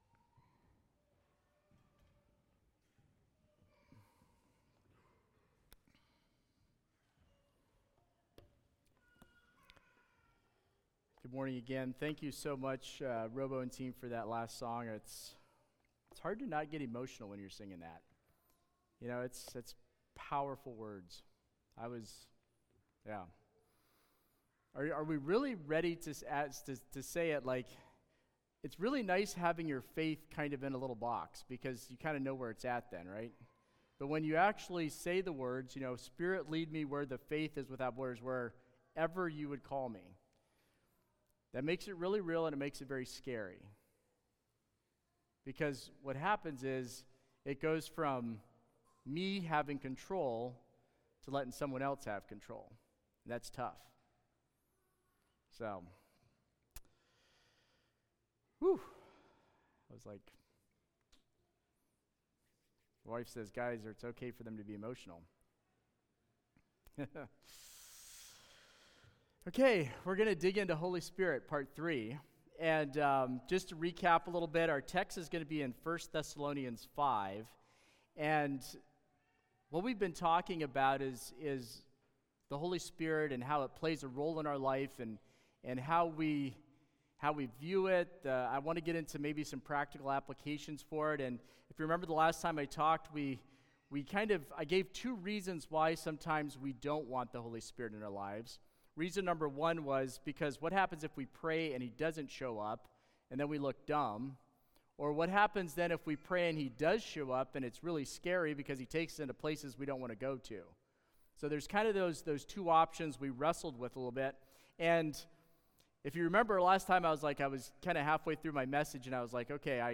Sermons | Christian Life Mennonite